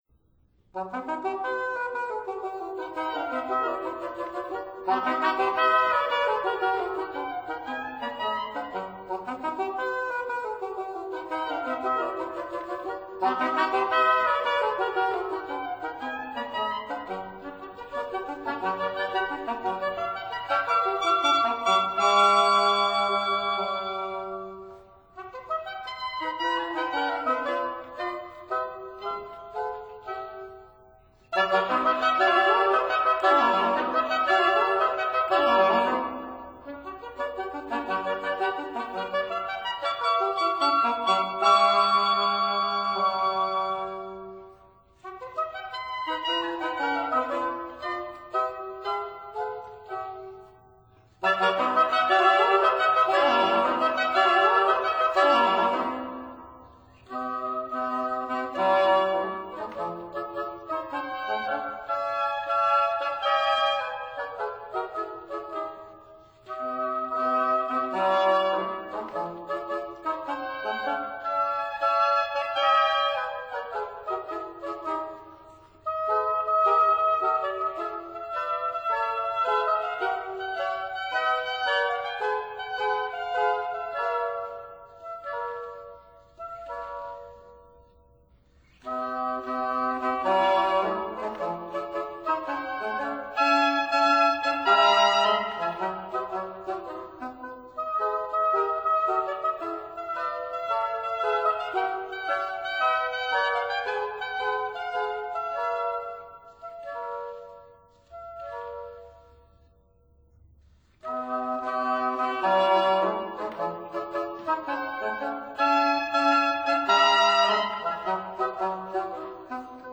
Trio for Two Oboes & English Horn in C major, Op. 87
Trio for Two Oboes & English Horn in C major
(Period Instruments)